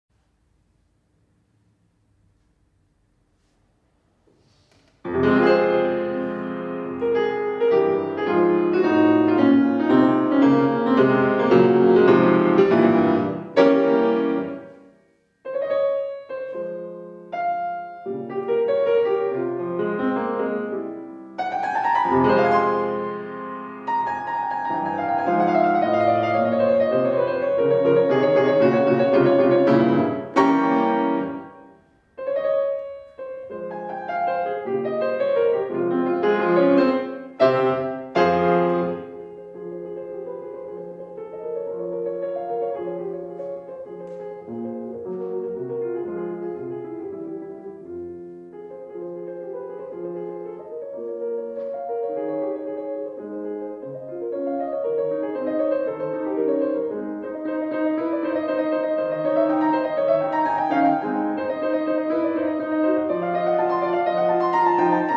IV OTTOBRE MUSICALE A PALAZZO VALPERGA - Gli Otto Improvvisi di Franz Schubert
piano